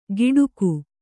♪ giḍuku